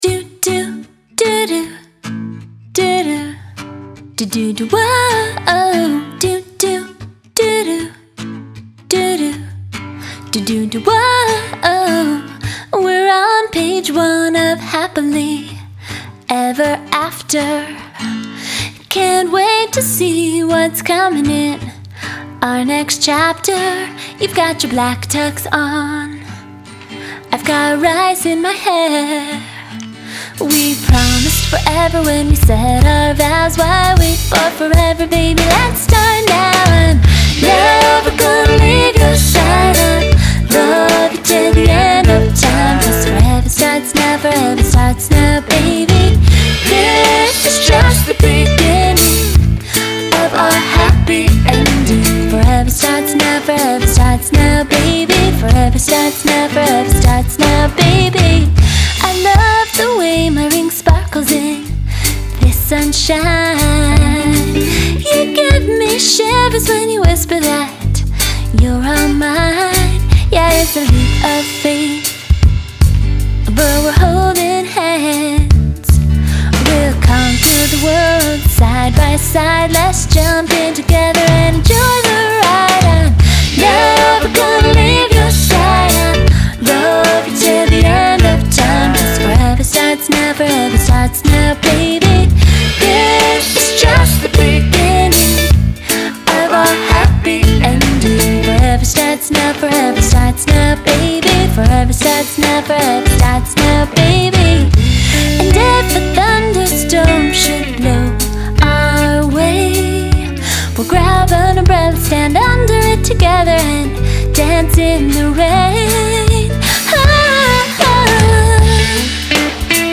I wanted to write a fun and not-too-sappy love song that portrays the joy and optimism of saying “I do.”
background vocals, guitars, drums